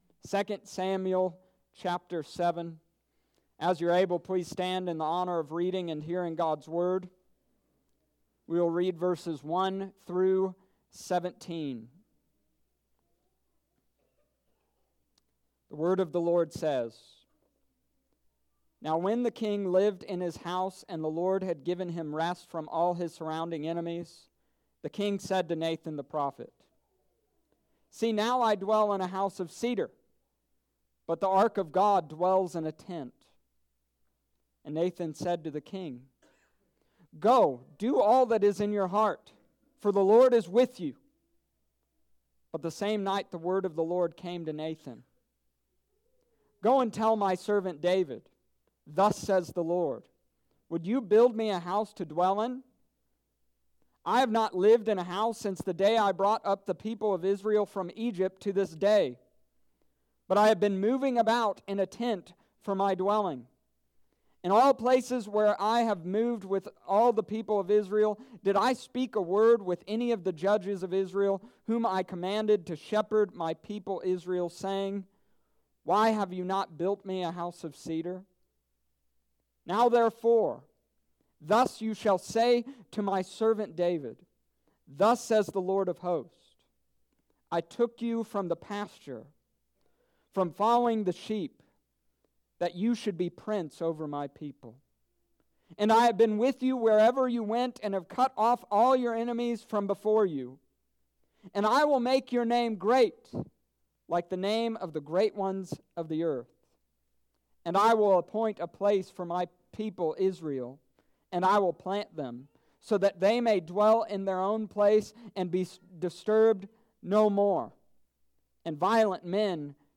The Ever- Reigning Seed, 2 Samuel chapter 7 verses 1-17 Dec. 22nd, 2019 Sunday Morning Service.